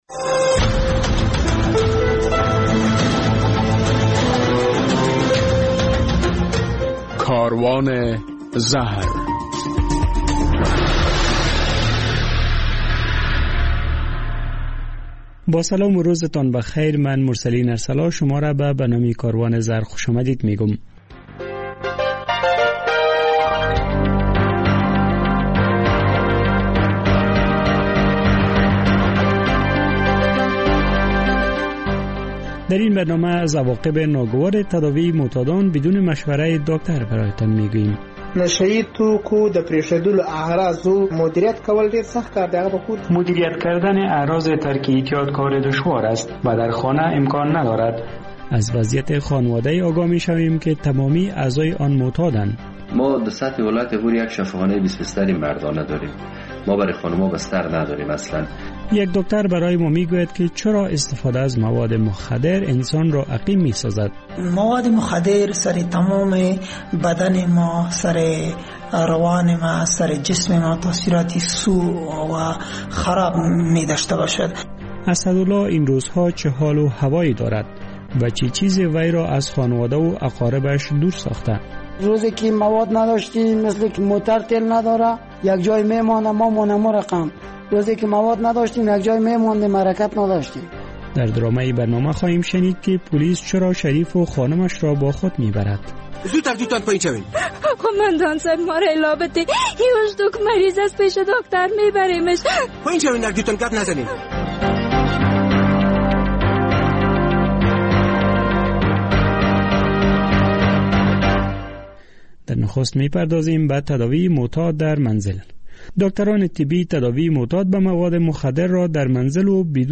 در برنامه این هفته کاروان زهر می‌شنوید که داکتران از عواقب ناگوار تداوی معتاد در منزل بدون مشوره داکتر هُشدار می‌دهند، در یک گزارش دیگر از وضعیت خانواده‌ای برای تان می‌گوییم که تمامی اعضای آن به مواد مخدر معتاد اند، در مصاحبه با یک داکتر می‌شنویم که اعتیاد سبب عقیم شدن استفاده کننده مواد مخدر...